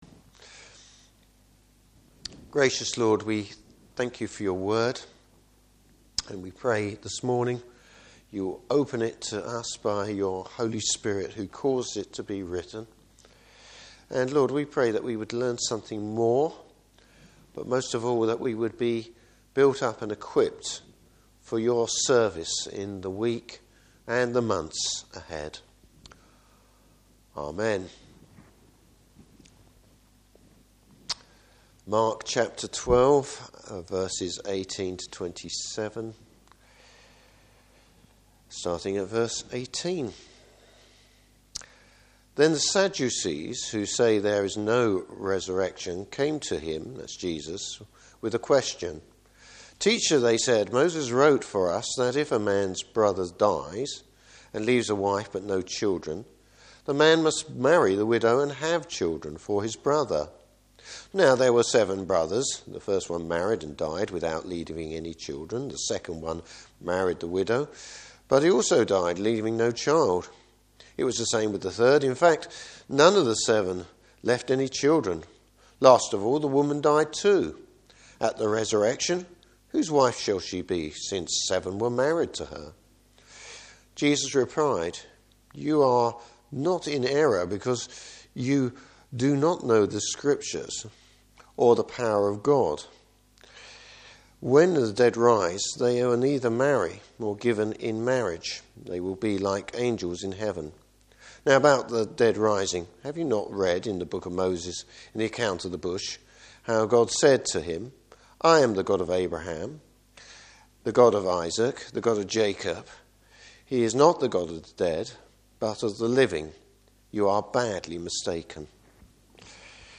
Service Type: Morning Service Understanding scripture and the power of God.